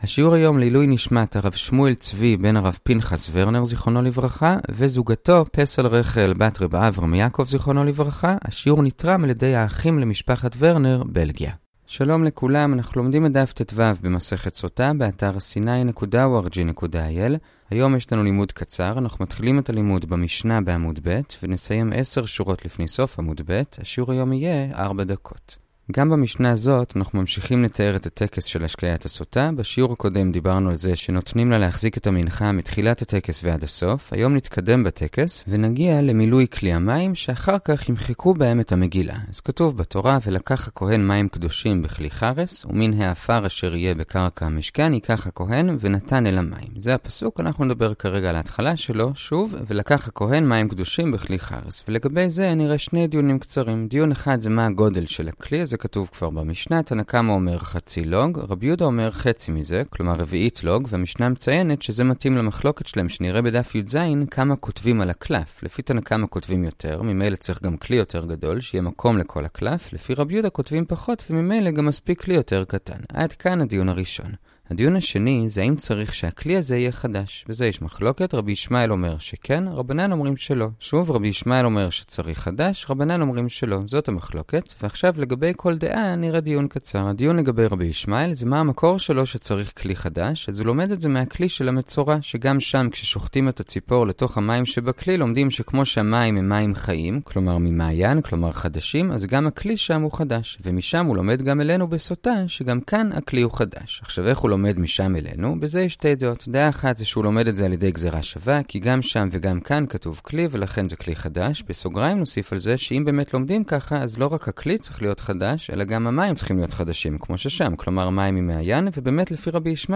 שיעור גמרא פשוט וברור כפי שמעולם לא שמעת. וב15 דקות בלבד.